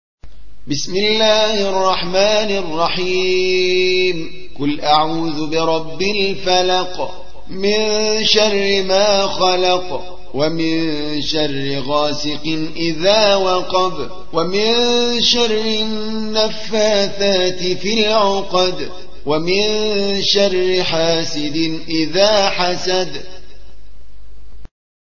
113. سورة الفلق / القارئ